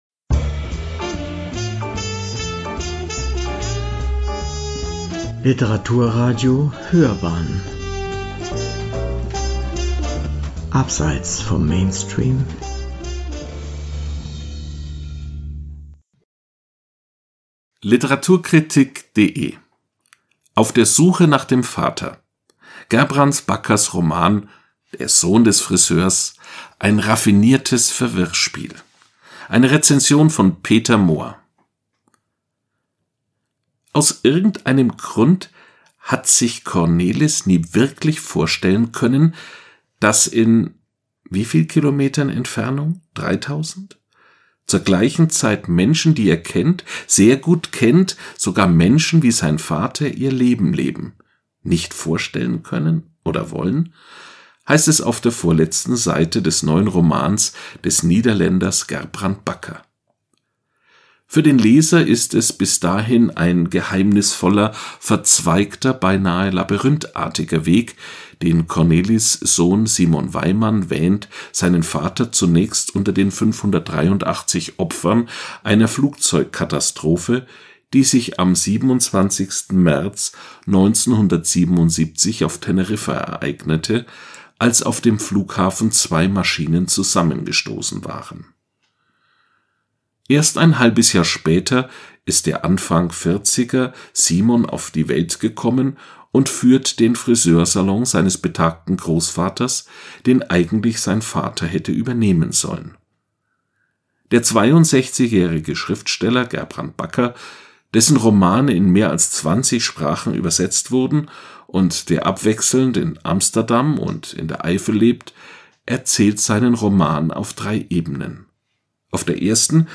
Eine Rezension
Sprecher